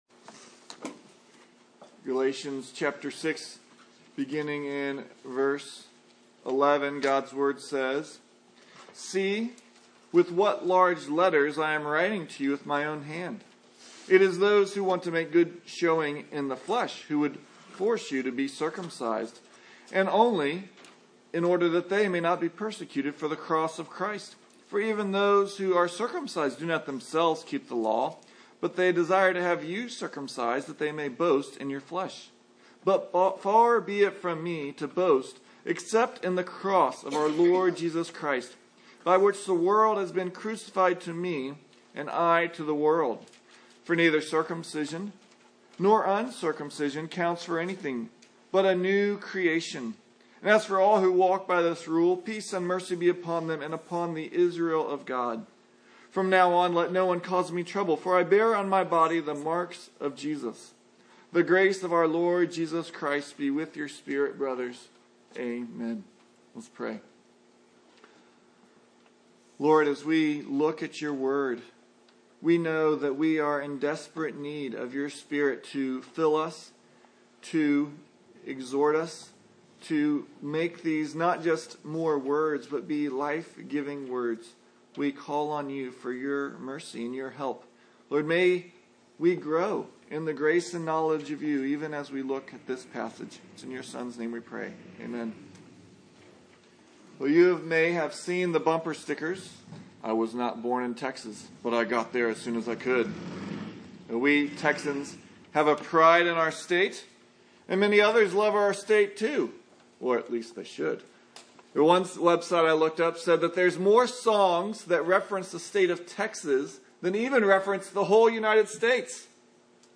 Passage: Galatians 6:11-18 Service Type: Sunday Morning